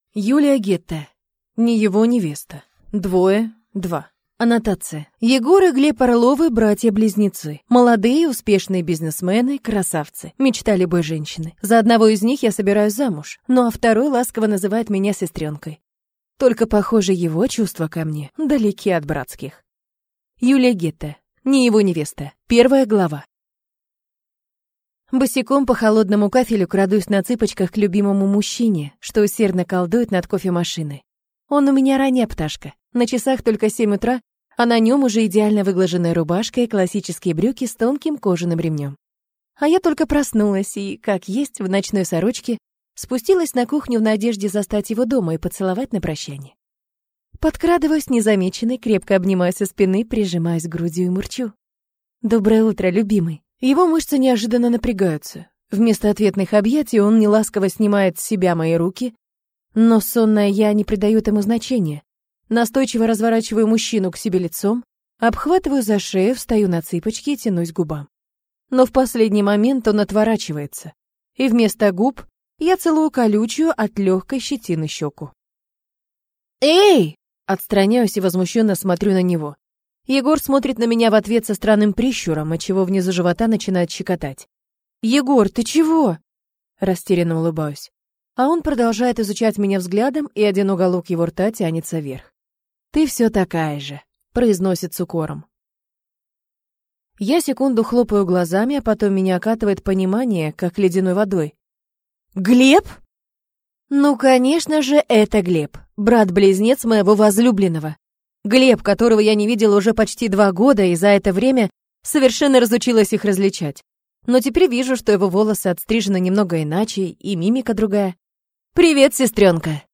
Aудиокнига Не его невеста